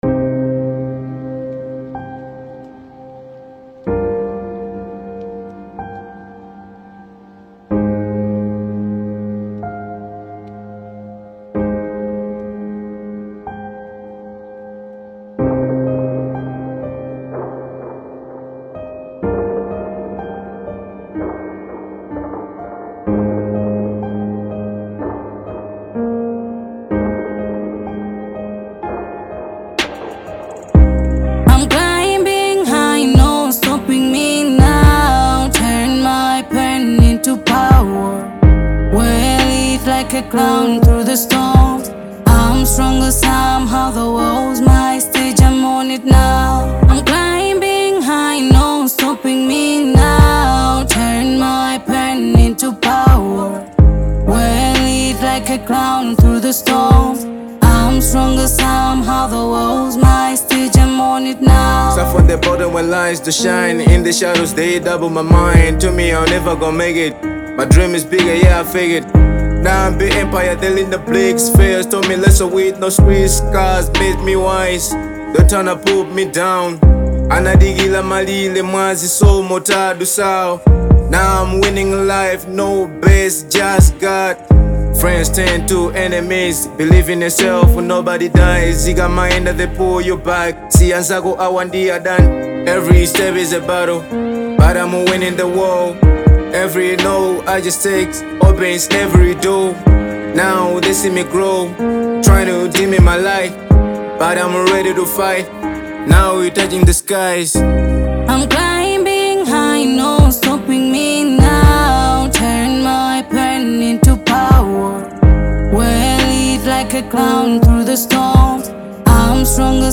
Genre : Afro Soul